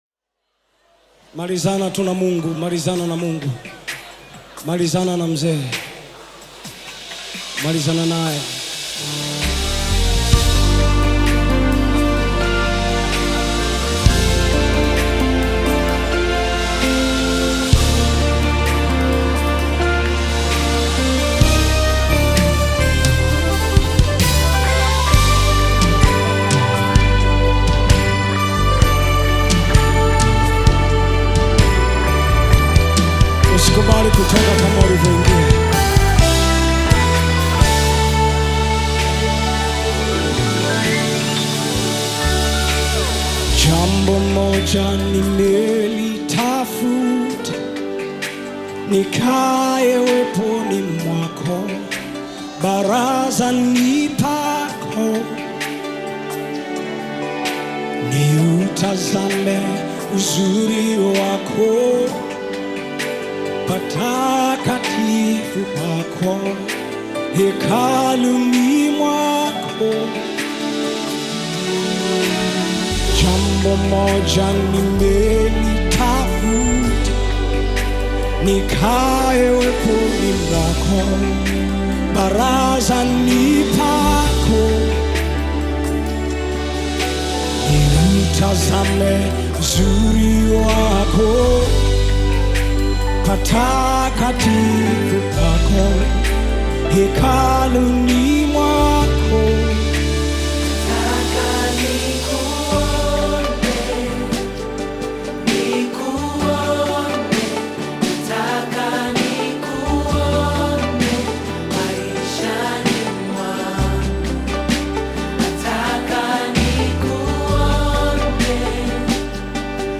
Nyimbo za Dini music
Gospel music track
Tanzanian gospel artist, singer, and songwriter